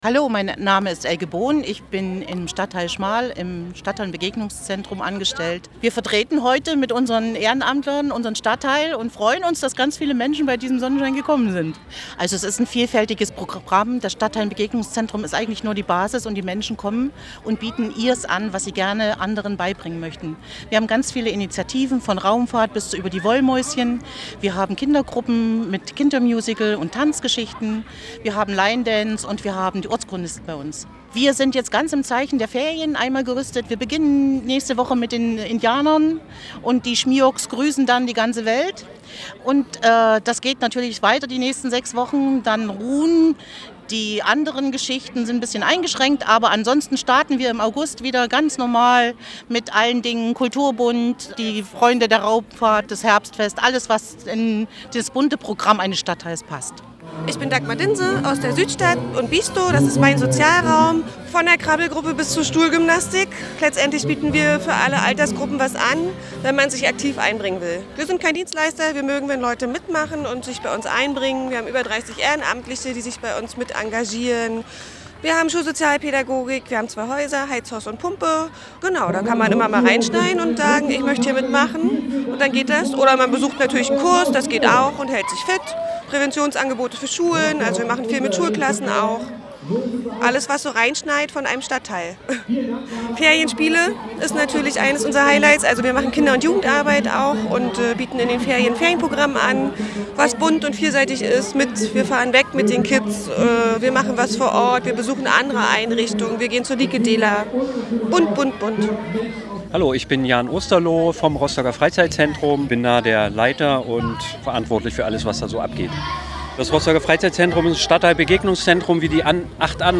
Am Mittwoch, den 04.07., versammelten sich neun Stadtviertel Rostocks an einer langen „Back“ – also an einem langem Tisch, um ihre Stadtteil-Begegnungszentren und das Leben in ihren Orten vorzustellen.
Das haben wir die Menschen dort gefragt: